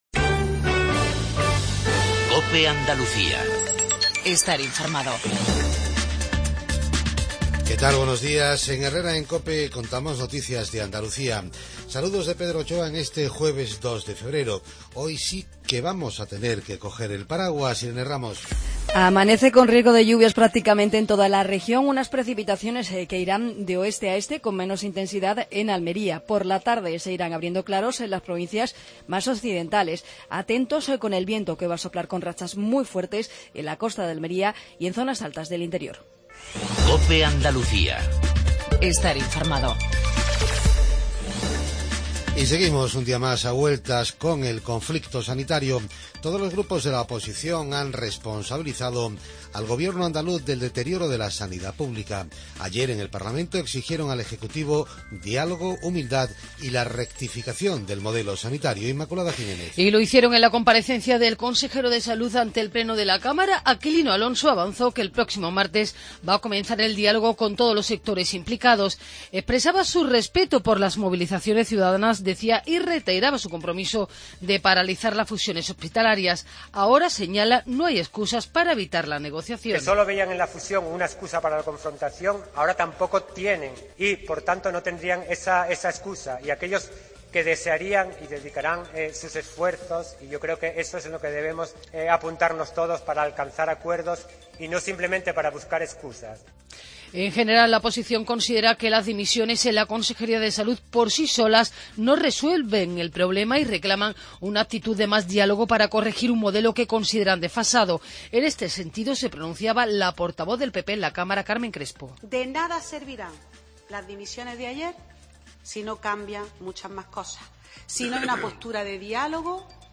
INFORMATIVO REGIONAL MATINAL 7:20